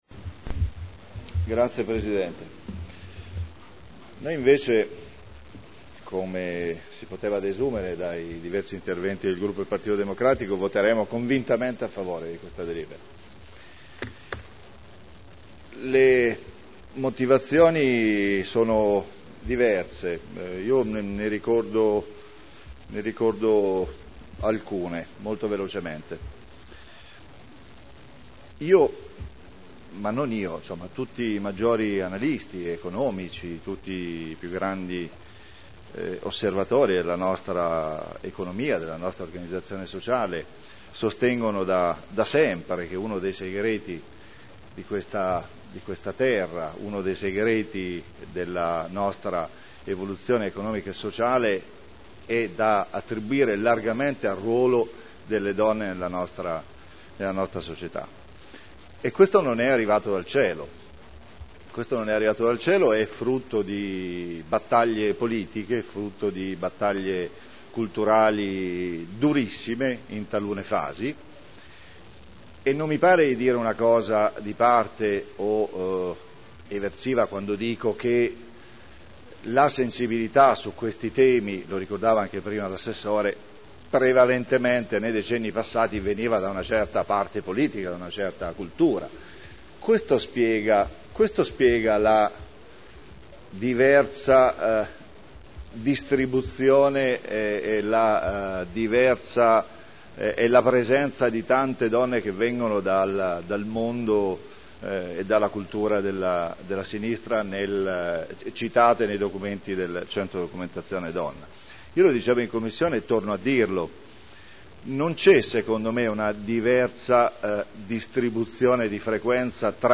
Paolo Trande — Sito Audio Consiglio Comunale